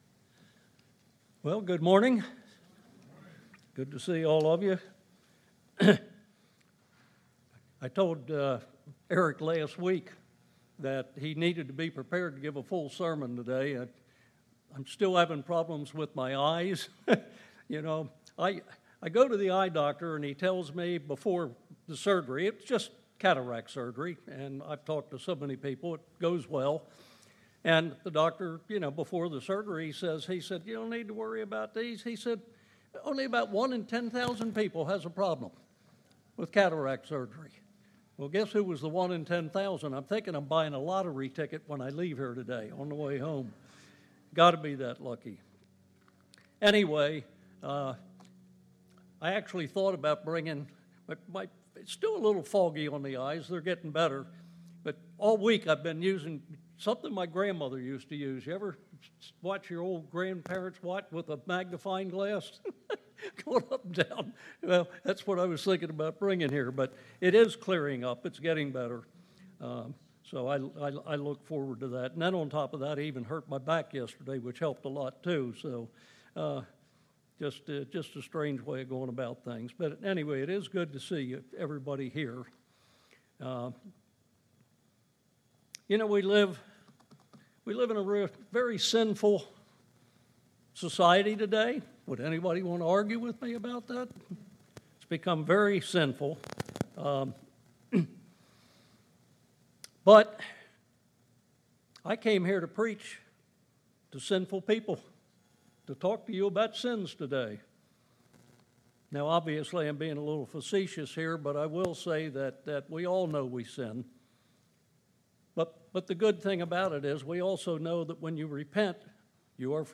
Sermons
Given in Murfreesboro, TN